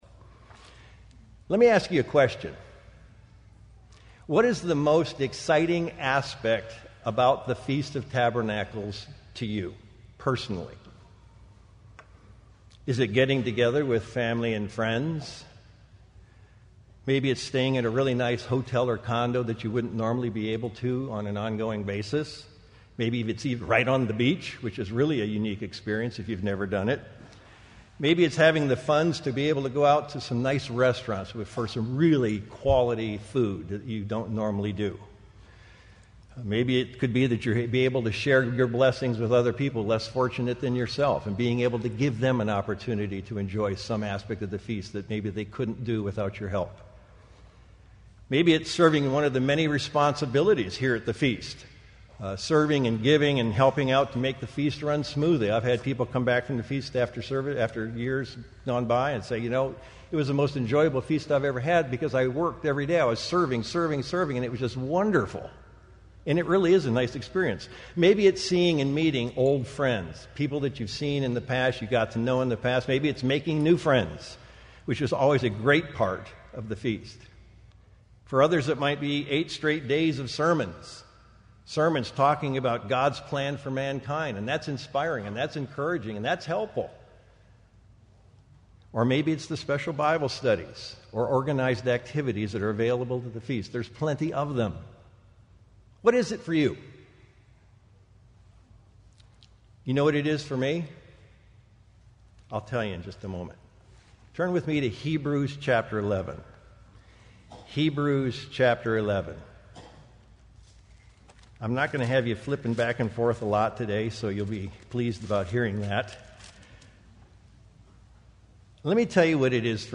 This sermon was given at the Oceanside, California 2016 Feast site.